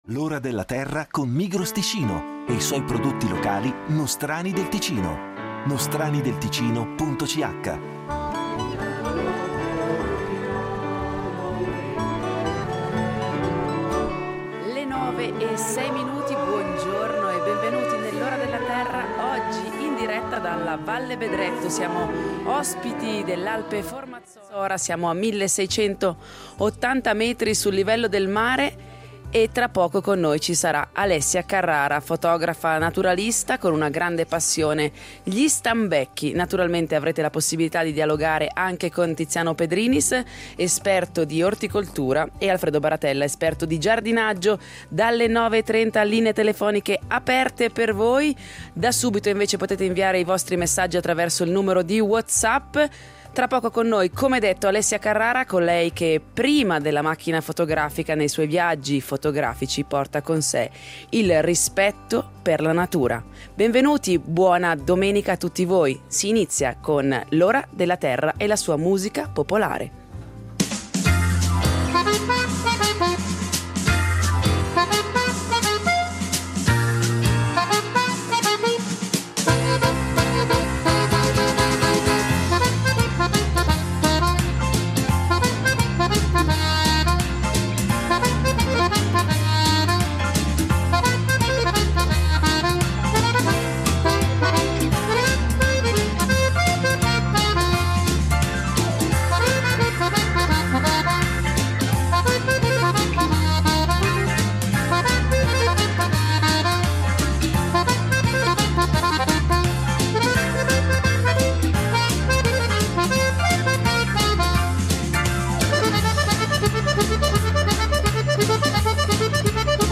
L’Ora della Terra sarà in diretta dalla Valle Bedretto, ospite dell’Alpe Formazzora, a 1680 metri sul livello del mare.